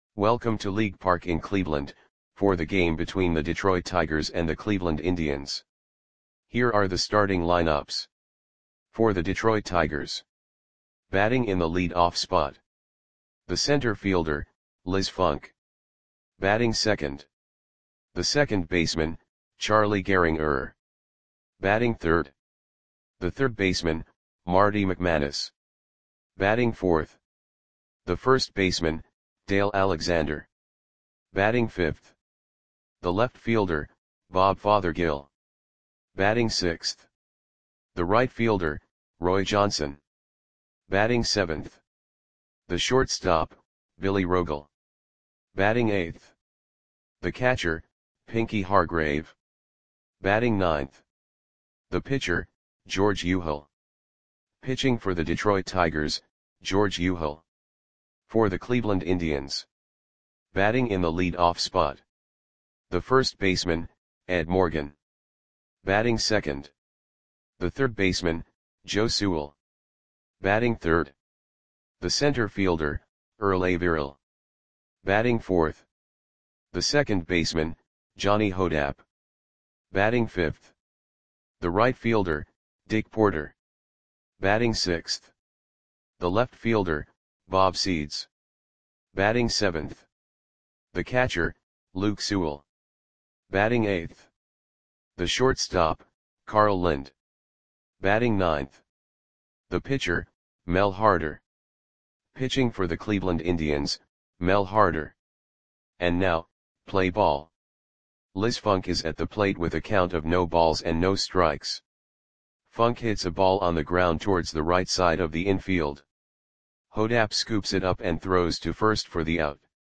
Audio Play-by-Play for Cleveland Indians on June 1, 1930
Click the button below to listen to the audio play-by-play.